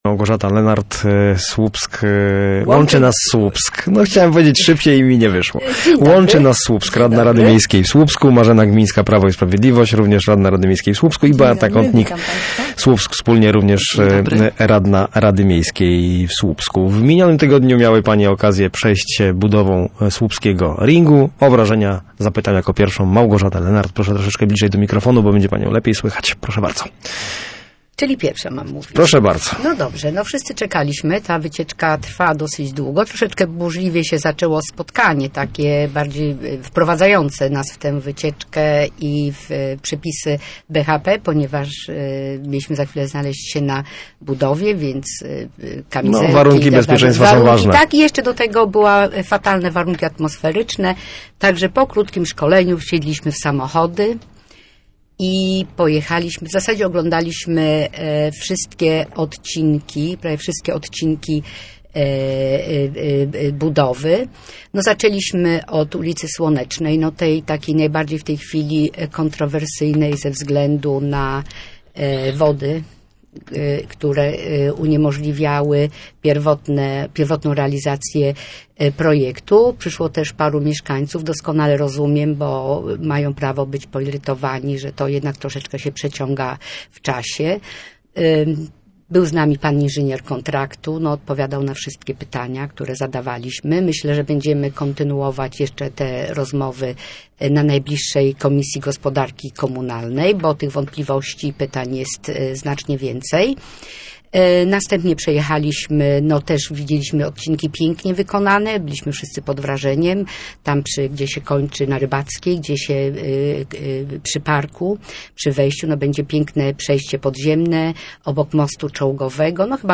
Powrót Urzędu Morskiego do Słupska to dobra wiadomość - uważają goście Miejskiego Programu Radia Gdańsk - Studio Słupsk 102 FM.